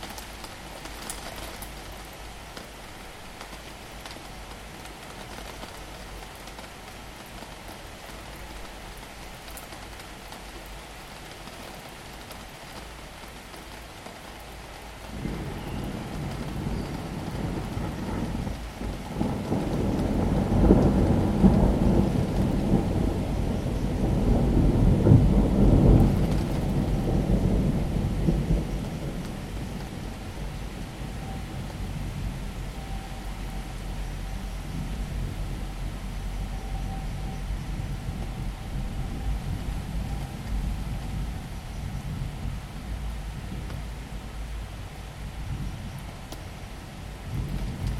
高级音响效果 雷雨和鸟类2
描述：雷声、雨声和鸟叫声的FX声景。用H2next录制的。